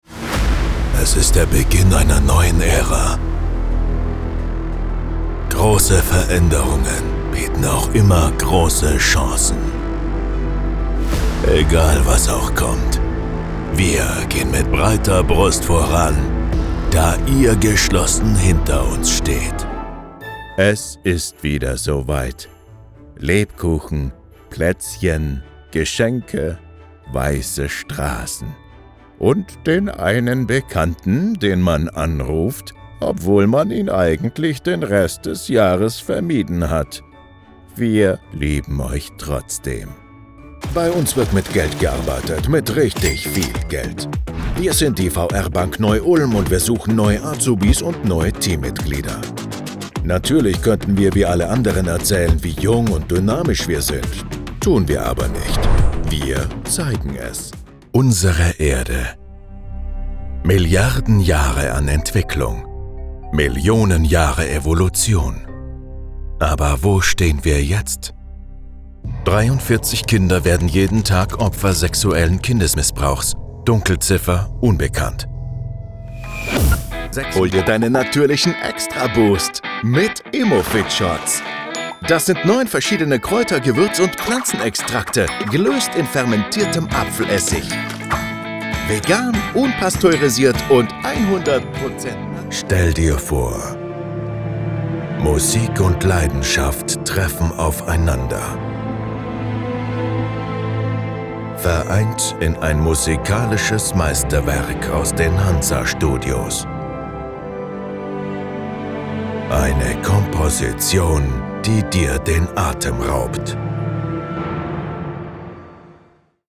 Demoreel
Mit meiner stimmlichen Vielseitigkeit, die von tief und sonor bis frisch und lustig reicht, verleihe ich Ihren Werbungen, Imagefilmen, Hörbüchern, E-Learning-Inhalten, Animationen, Telefonansagen und vielem mehr eine einzigartige Klangfarbe.
Audition_Demoreel.mp3